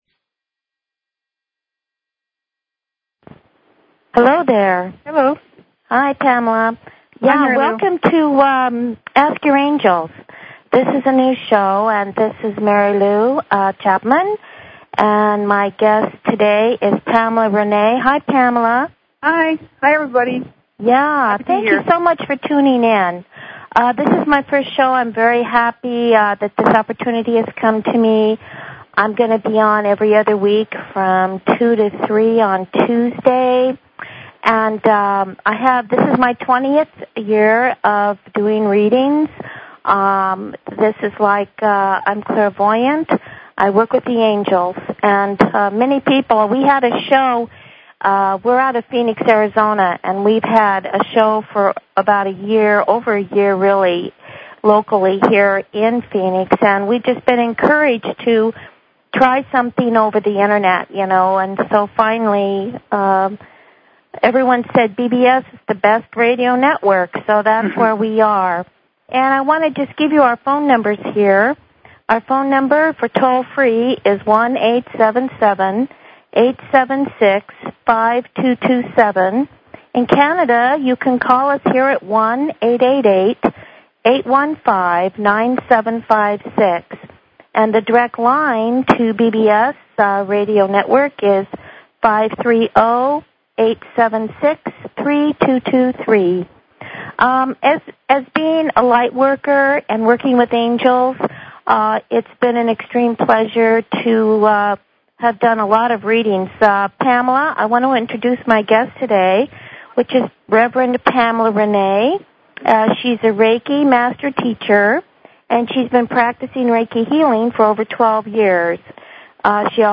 Talk Show Episode, Audio Podcast, Ask_Your_Angels and Courtesy of BBS Radio on , show guests , about , categorized as